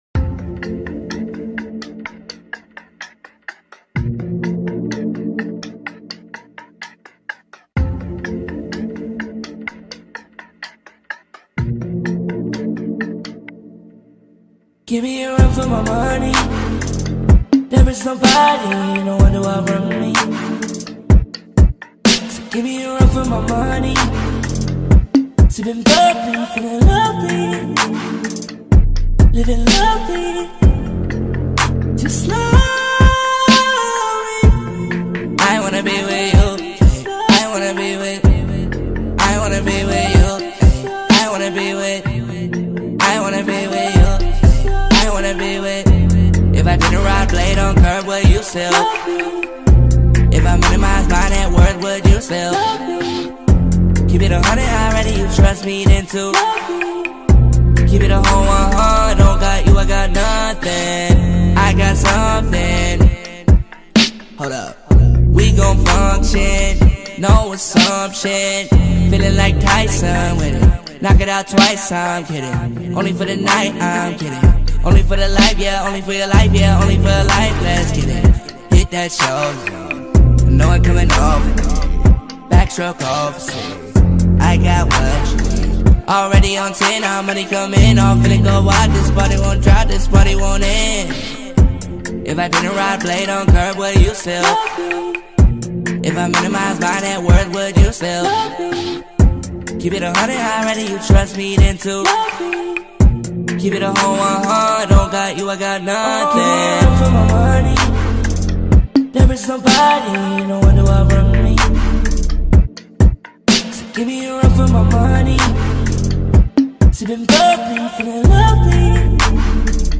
R & B
• Genre: R & B